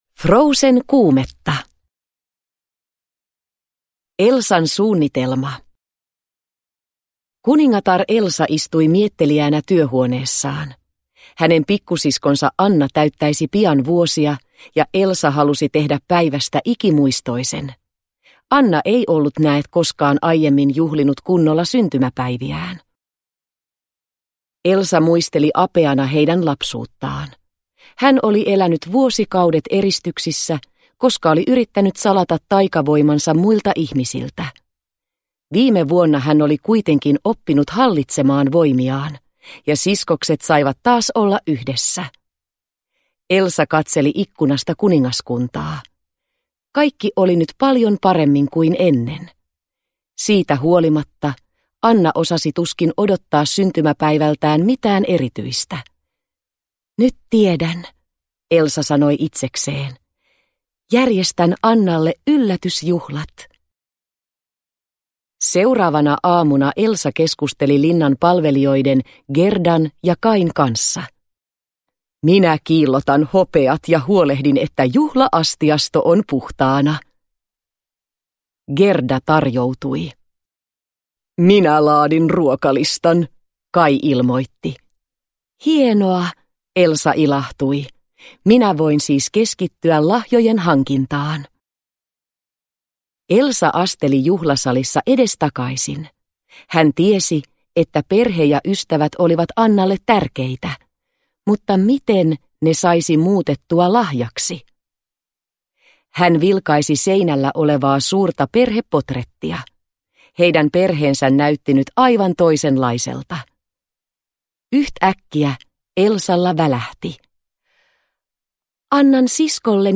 Frozen-kuumetta – Ljudbok – Laddas ner